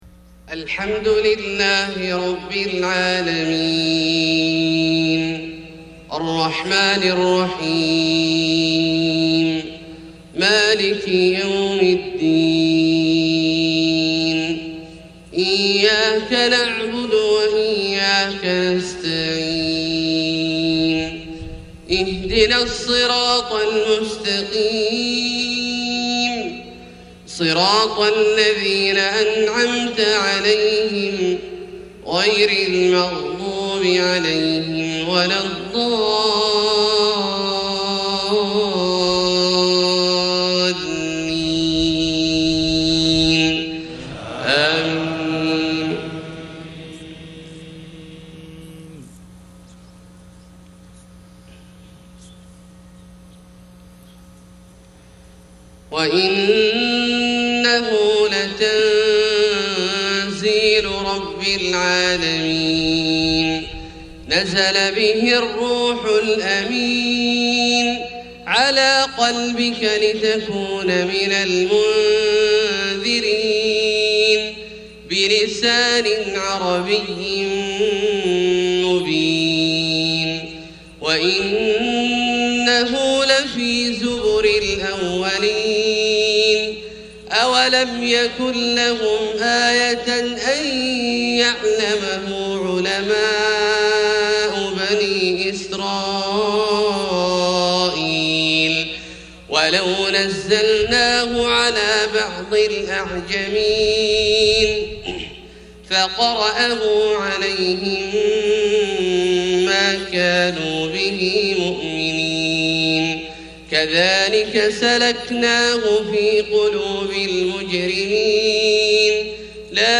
فجر 9-3-1433هـ من سورة الشعراء {192-227} > ١٤٣٣ هـ > الفروض - تلاوات عبدالله الجهني